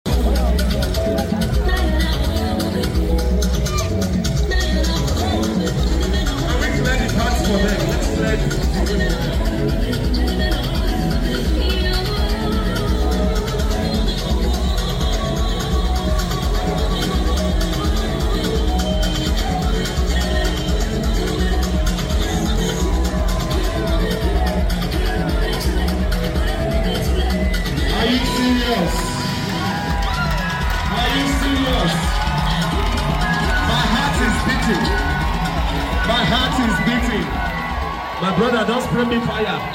🔥 Our Igbo dancers SHUT DOWN our graduation ceremony with vibes, grace, and unstoppable energy!